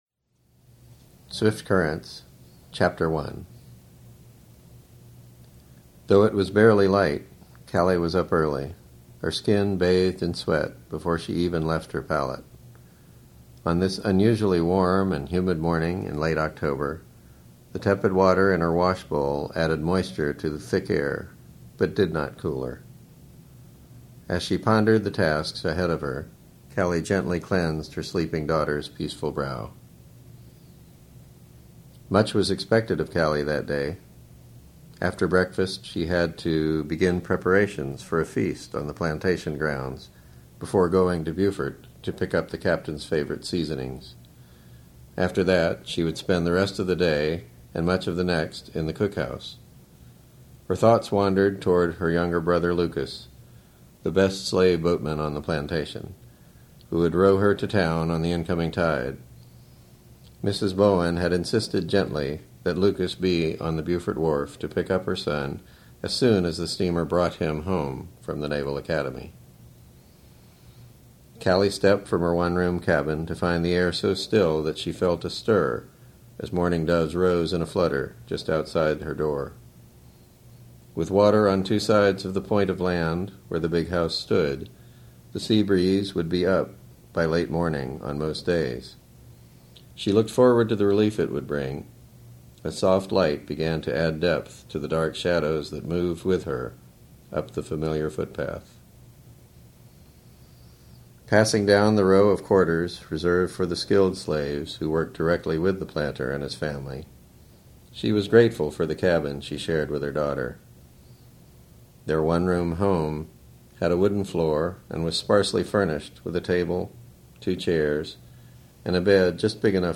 read by the author